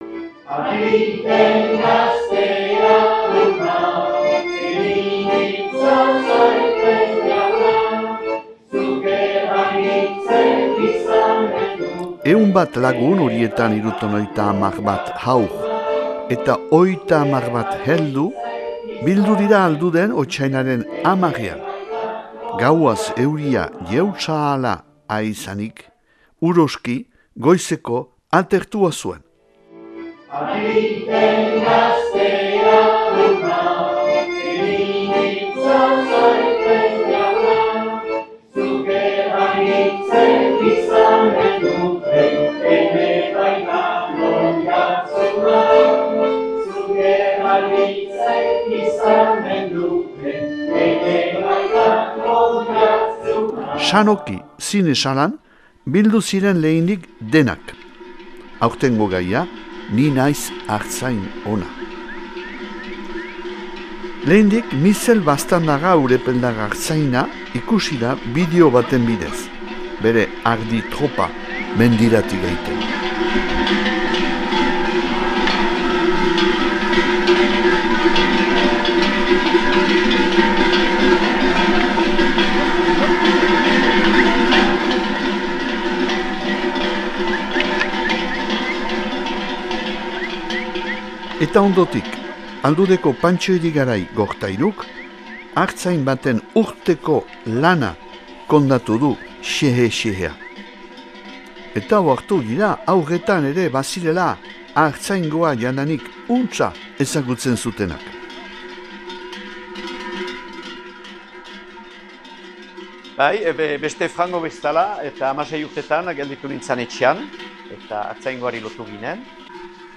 70 bat haur, 30 bat katixima egilek lagundurik, bildu dira otsailaren 10 ean Alduden.